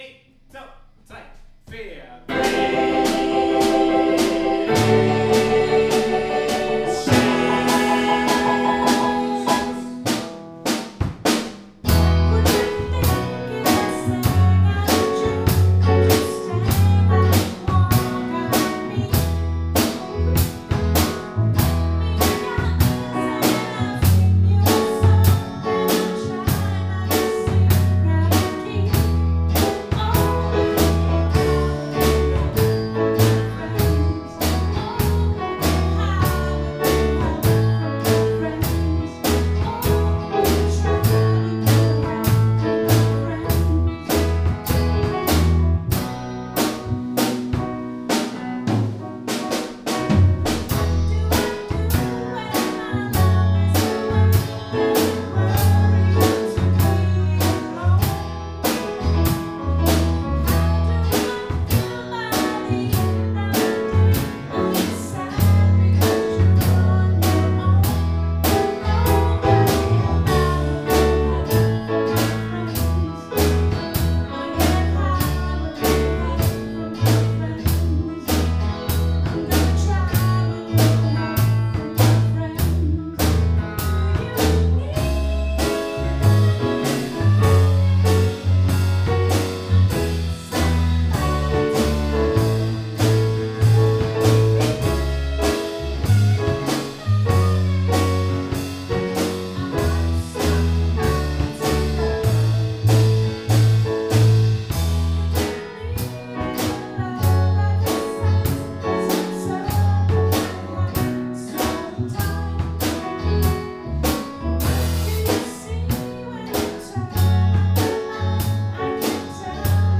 “With a little help from my friends” fra Rehearsal Spring 2020 af MR1.